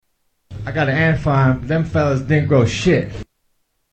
Category: Comedians   Right: Personal
Tags: Comedians Mitch Hedberg Sounds Mitchell Lee Hedberg Mitch Hedberg Clips Stand-up Comedian